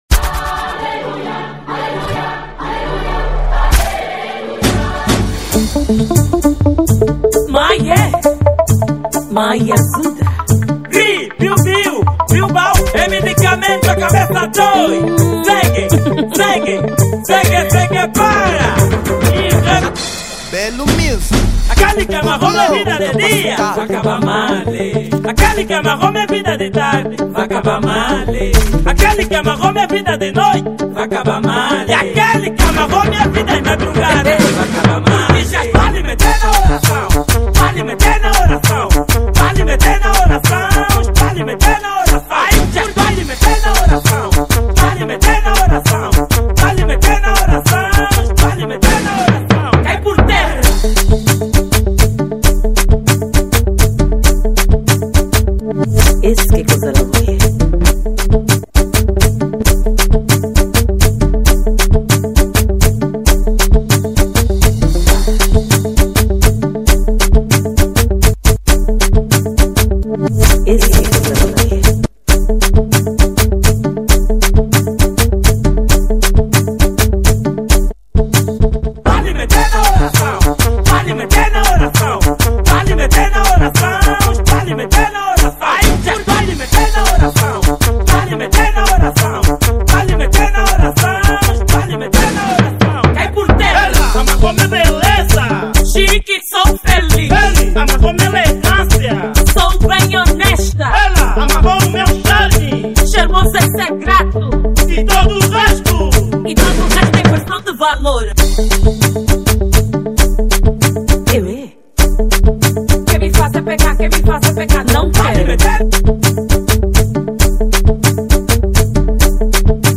Género : Afro house